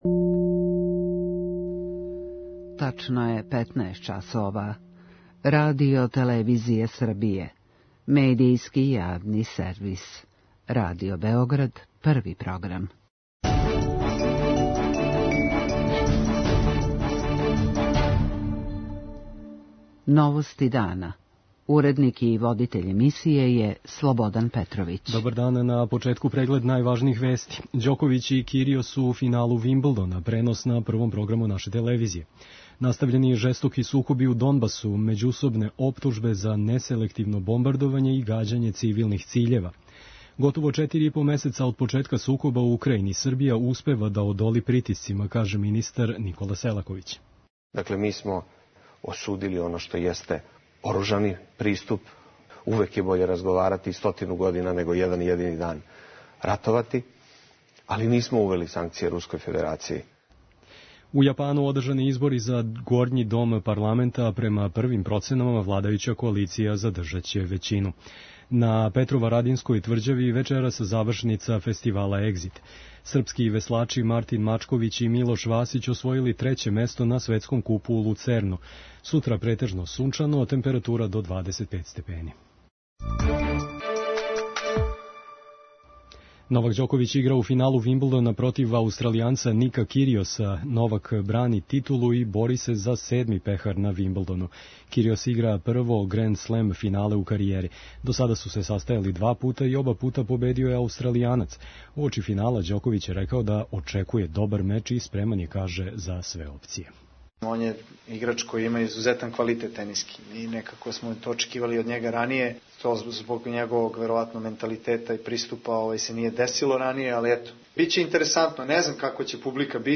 Селаковић је додао да је политика повлачења признања независности самопроглашеног Косова жива и активна. преузми : 5.57 MB Новости дана Autor: Радио Београд 1 “Новости дана”, централна информативна емисија Првог програма Радио Београда емитује се од јесени 1958. године.